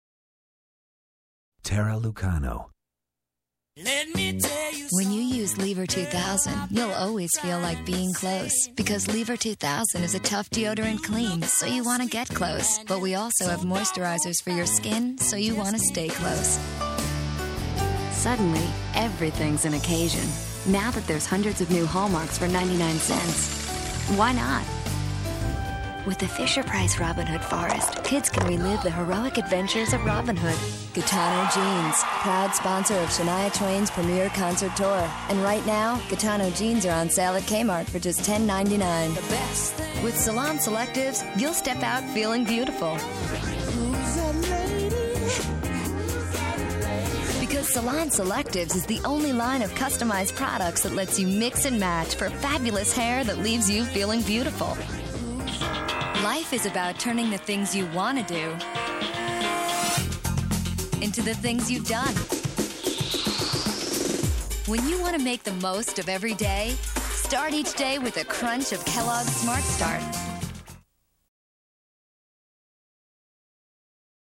Voiceover : Commercial : Women
Commercial Demo